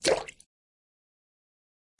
水 " 耳光005
描述：一些声音是我用Tascam DR05和一个梦想的非常简约的设置录制的。
标签： 水产 海洋 河流 波浪 滴灌 崩溃 BLOOP 游戏 运行 blop 运行 浇注 湿 飞溅 电影 水产
声道立体声